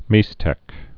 (mēstĕk)